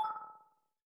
Destruction 5.wav